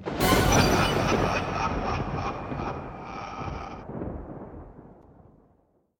_finish_level.ogg